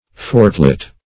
fortlet - definition of fortlet - synonyms, pronunciation, spelling from Free Dictionary Search Result for " fortlet" : The Collaborative International Dictionary of English v.0.48: Fortlet \Fort"let\, n. A little fort.